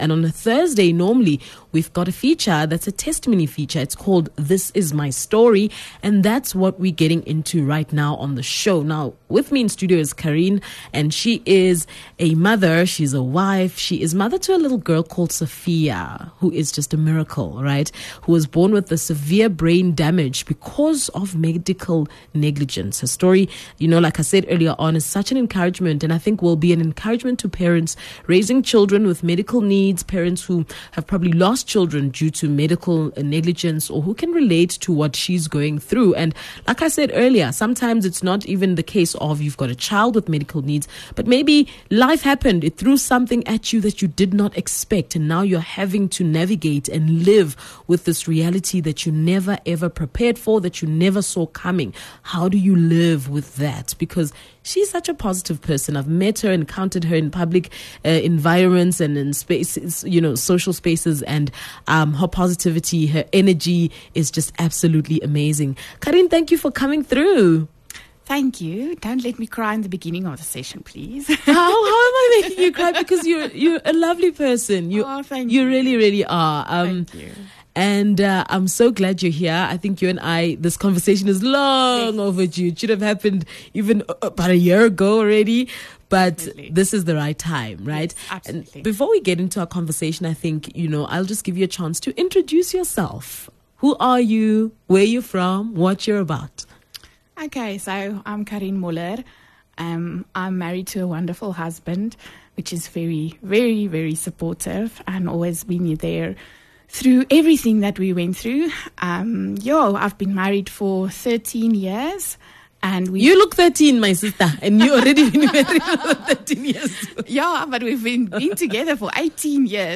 On this channel, we share some of our presenters most valuable and encouraging links from their shows, interviews with guests and other other valuable content.